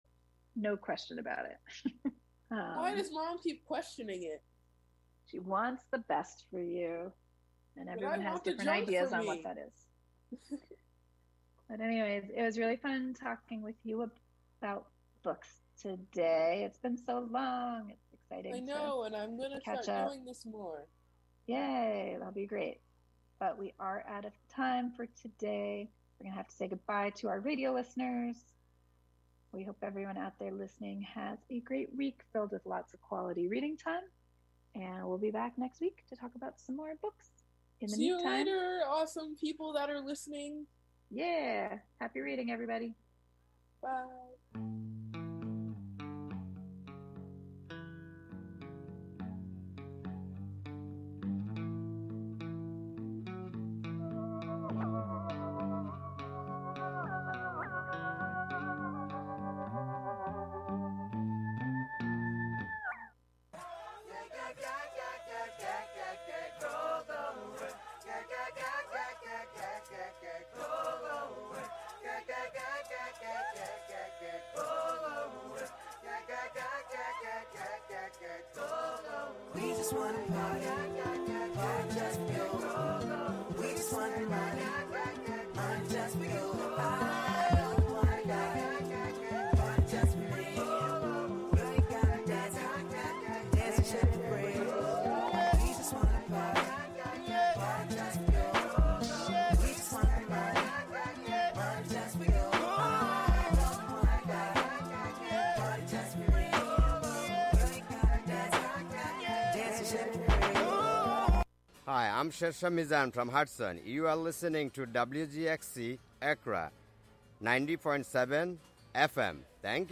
Through Censored, The Word Shop, and Our Town Our Truth, we dig into the topics that matter. Our container: Radiolab, an open, experimental, youth-led programming and recording space. Show includes local WGXC news at beginning, and midway through.